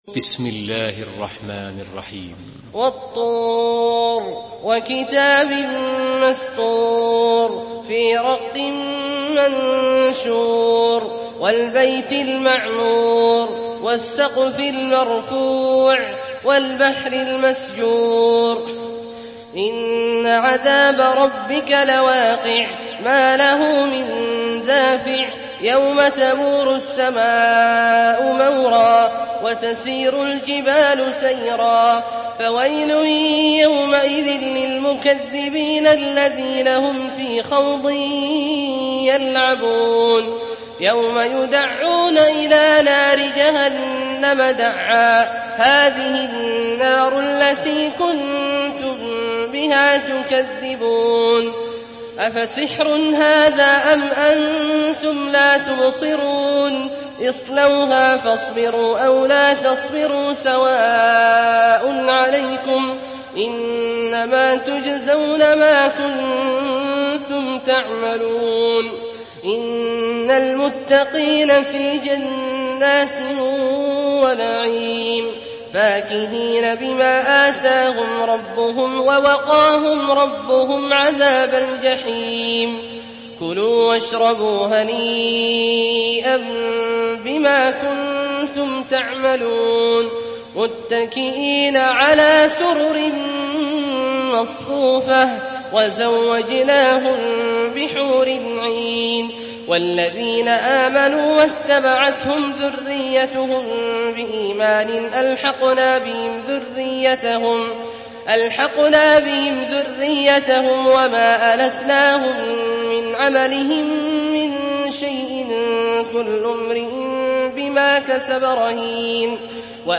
Surah Tur MP3 by Abdullah Al-Juhani in Hafs An Asim narration.
Murattal Hafs An Asim